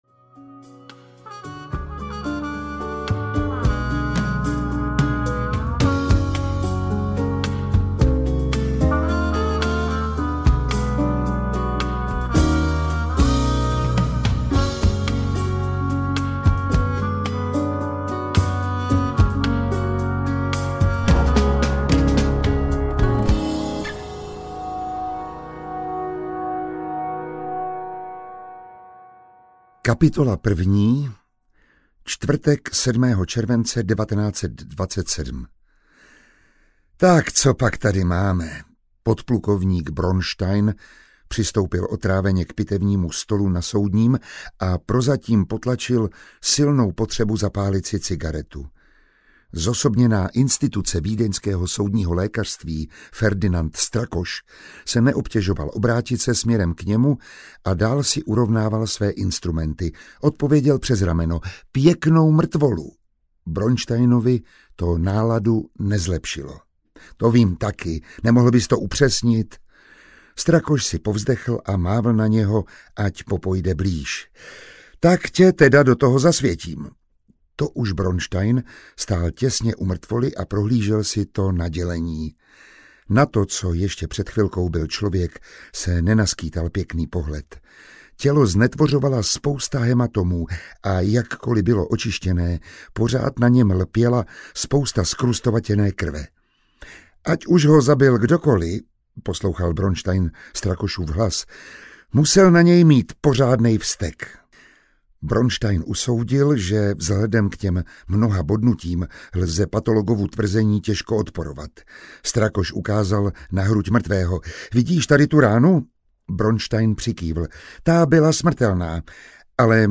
Horký červenec 1927 audiokniha
Ukázka z knihy
• InterpretMiroslav Táborský